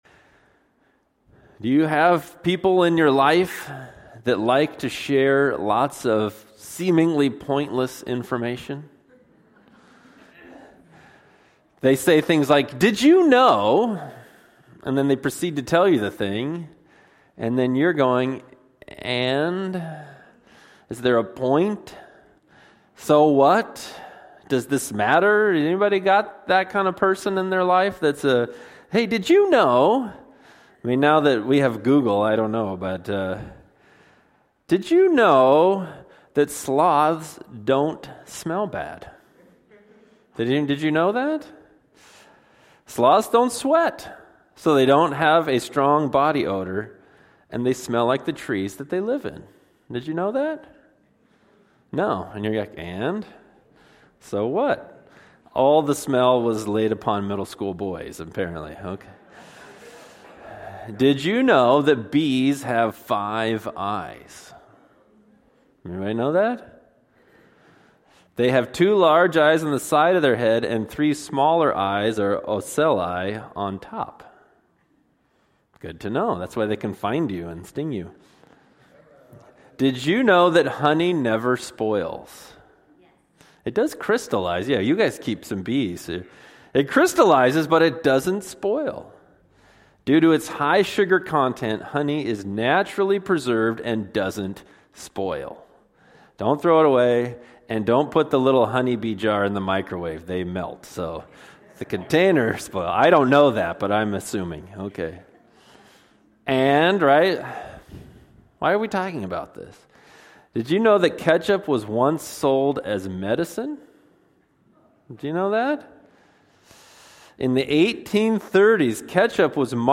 Sermons by Northwest Baptist Church (Bellingham, WA)